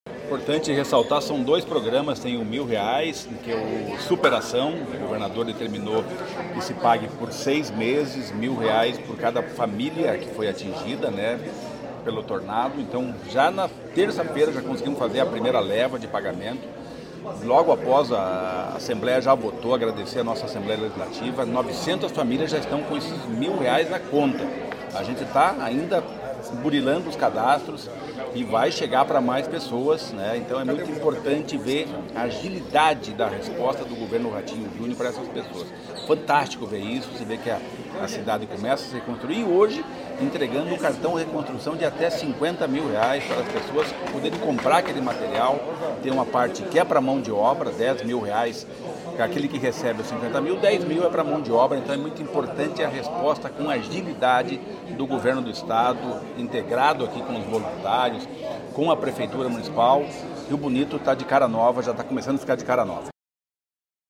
Sonora do secretário do Desenvolvimento Social e Família, Rogério Carboni, sobre a entrega dos primeiros cartões do programa Reconstrução